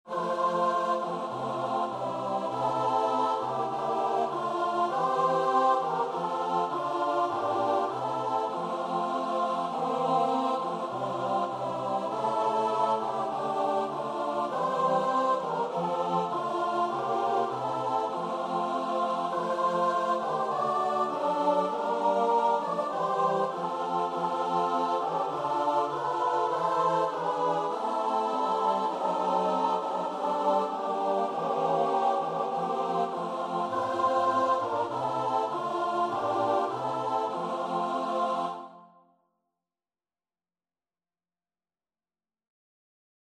Classical Trad. Round the Lord in Glory Seated Choir version
Free Sheet music for Choir
F major (Sounding Pitch) (View more F major Music for Choir )
4/4 (View more 4/4 Music)
Choir  (View more Intermediate Choir Music)
Christian (View more Christian Choir Music)